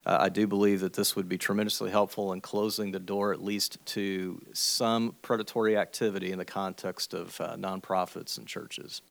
Richey, a pastor, said in a Wednesday hearing that predators know churches and charities are hungry for volunteers and exploit the vulnerable people within.